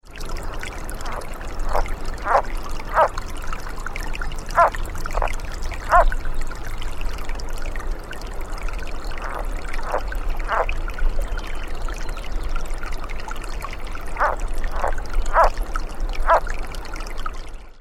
It is a short and rasping call often accelerated and rising at the end, sometimes preceded by calls that don't rise at the end.
Water flowing from snow-melt into the small lake can be heard in the background, along with occasional singing birds.
Sound This is an 18 second recording of calls made by a male frog sitting at the edge of the water with its head slightly out of the water (top picture on the left - notice the bubbles created while calling.) The sounds recorded are those produced by the frog in the air.